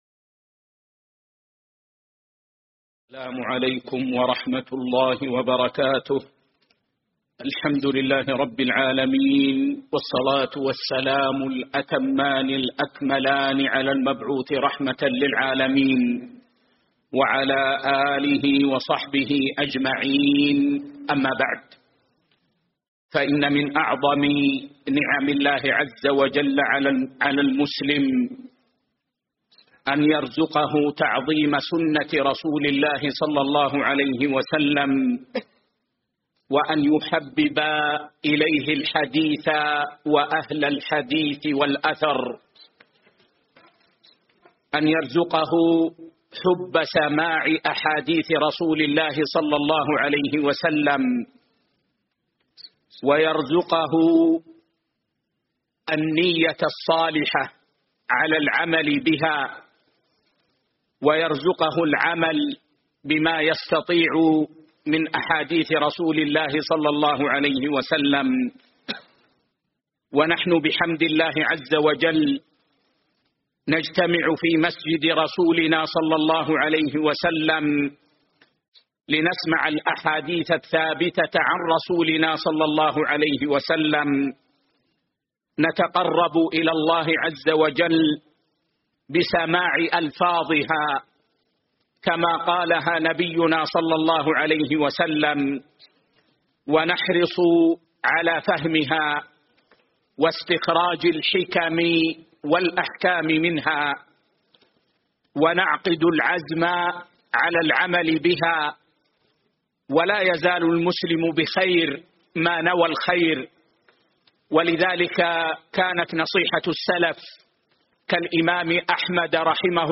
الدرس 116